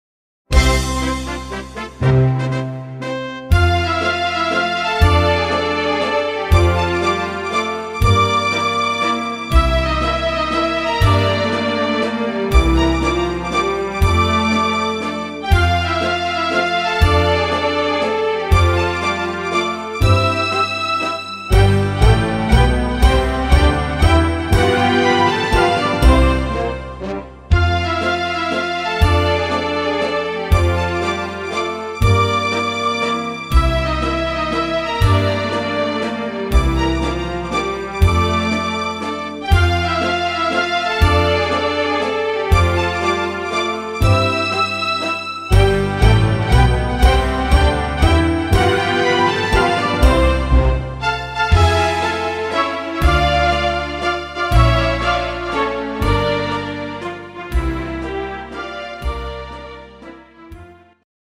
Wiener Walzer